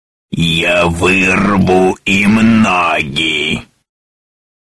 голосовые
злые